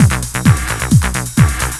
TECHNO125BPM 17.wav